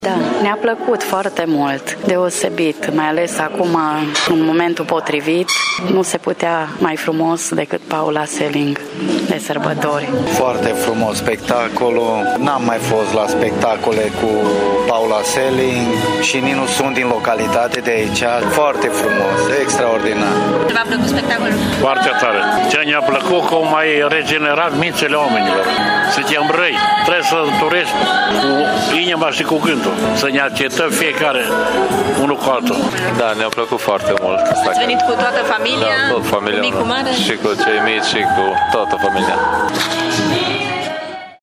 Pentru târgumureșeni acest spectacol a fost foarte bine venit mai ales acum în perioada pascală și au fost impresionați de vocea extraordinară a Paulei Seling: